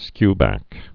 (skybăk)